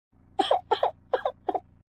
Cry Roblox Téléchargement d'Effet Sonore
Cry Roblox Bouton sonore